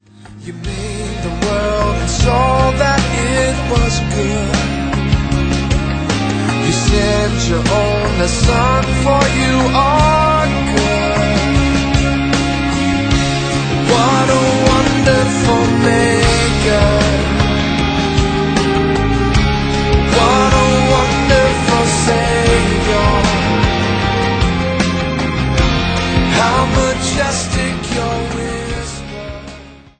This passionate live recording
traditional & modern worship songs & hymns of faith